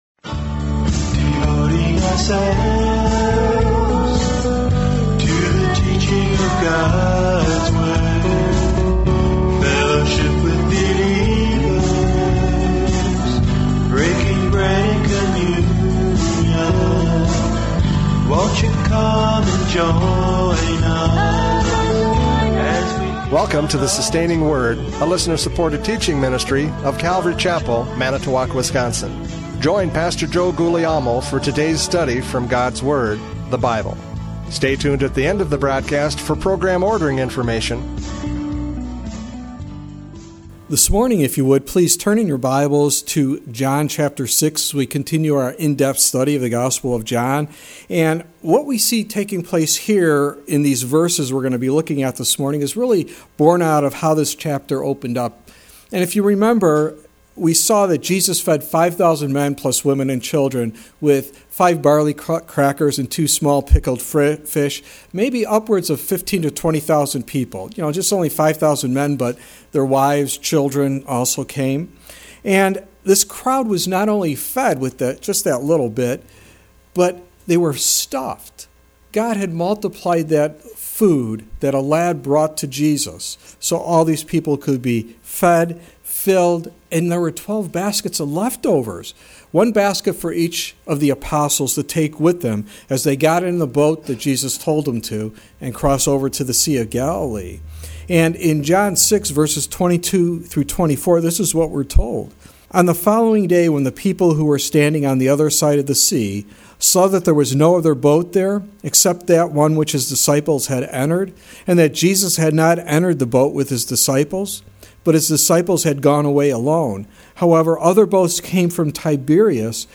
John 6:30-40 Service Type: Radio Programs « John 6:22-29 True Faith!